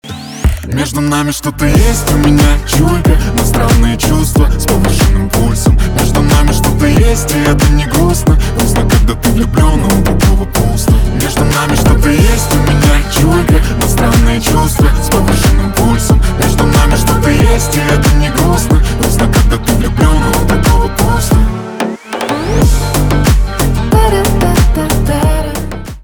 поп
гитара , басы